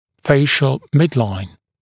[‘feɪʃl ‘mɪdlaɪn][‘фэйшл ‘мидлайн]срединная линия лица